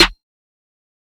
DY Krazy Snare 2.wav